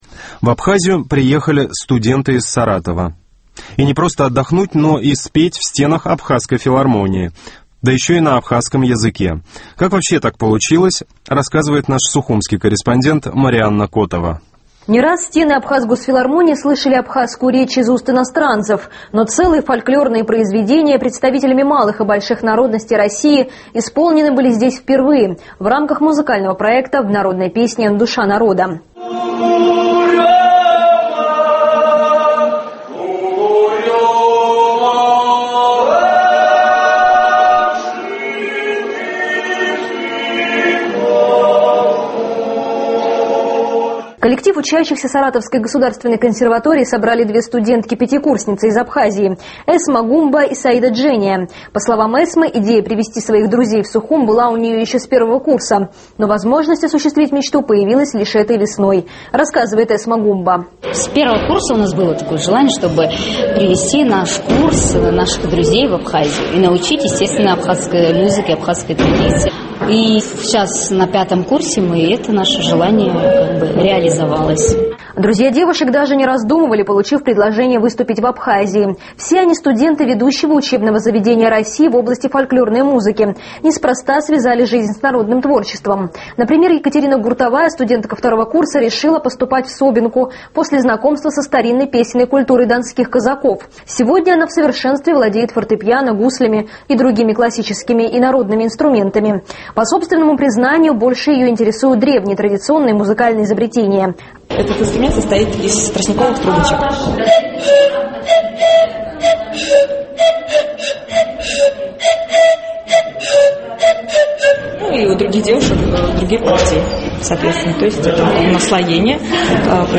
Российские студенты запели на абхазском